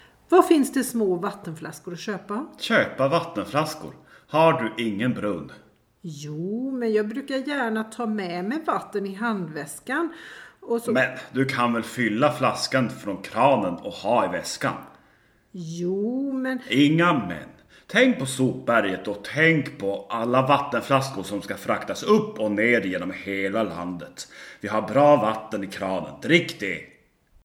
Soundfiles Dialog (schwedisch):